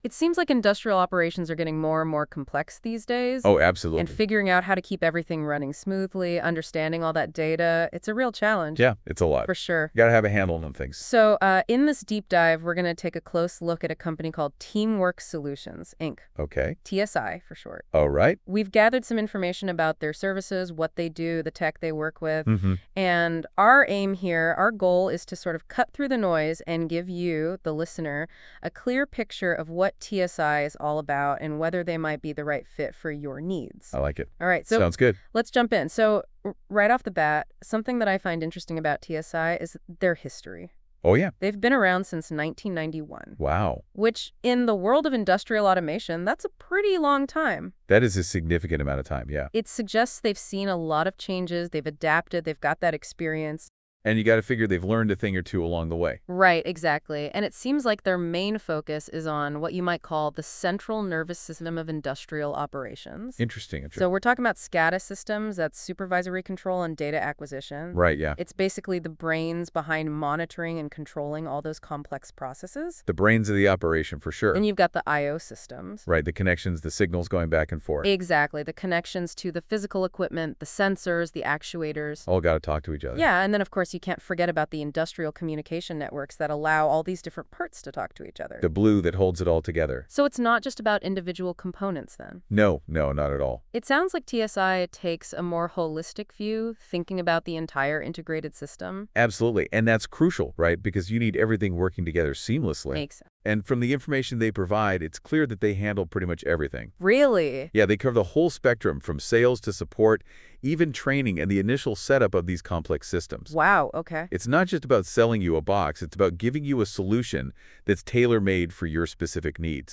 Introduction to Teamwork – Google Notebook LM Podcast